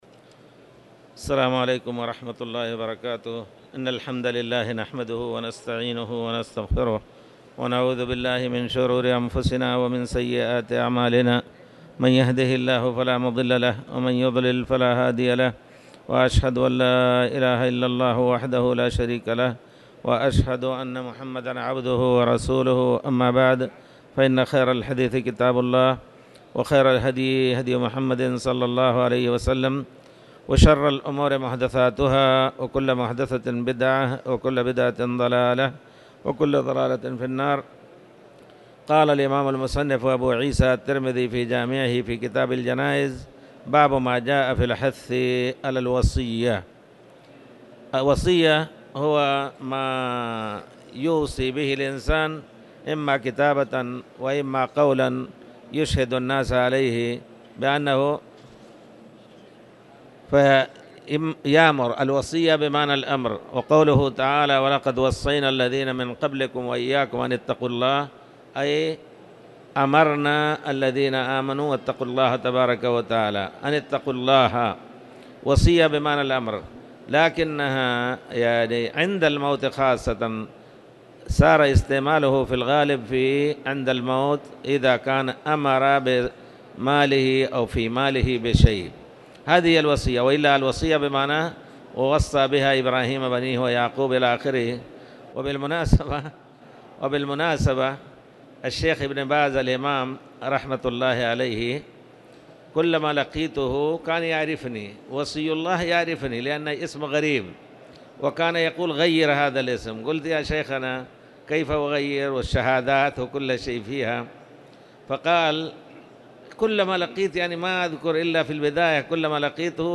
تاريخ النشر ٤ رمضان ١٤٣٧ هـ المكان: المسجد الحرام الشيخ